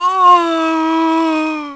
c_zombif1_dead.wav